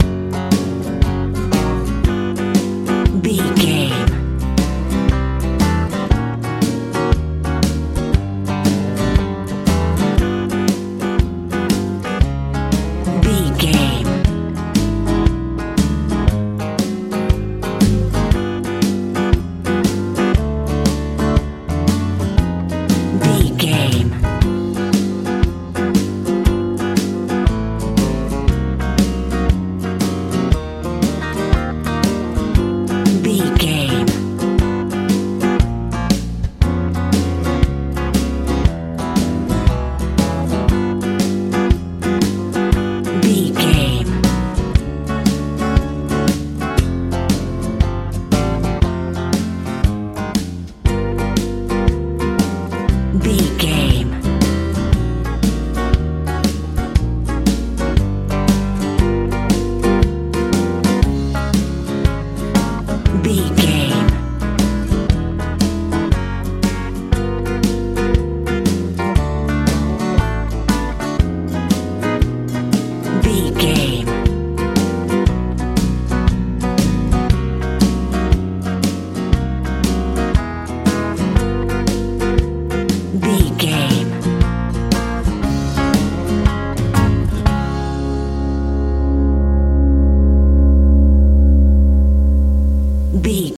country boogie feel
Ionian/Major
F♯
hopeful
acoustic guitar
electric guitar
bass guitar
drums
southern